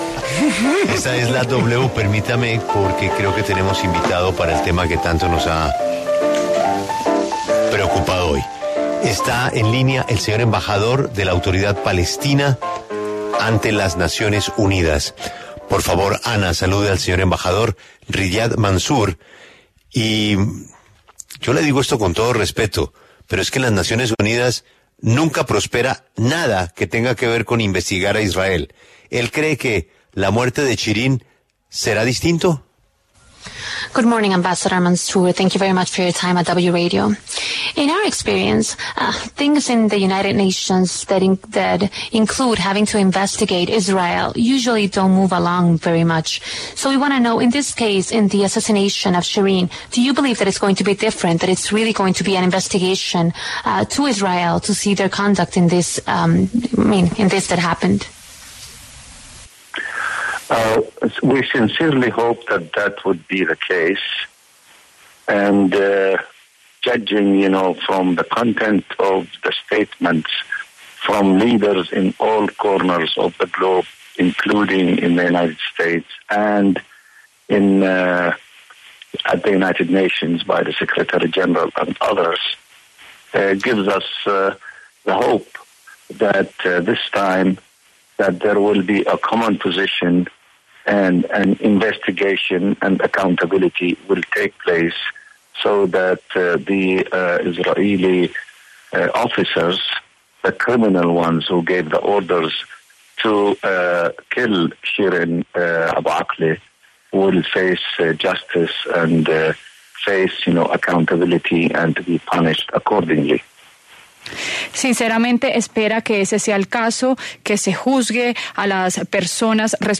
En diálogo con La W, el embajador de Palestina ante las Naciones Unidas, Riyad Mansour, se pronunció sobre el asesinato de la periodista Shireen Abu Akleh, del medio Al Jazeera.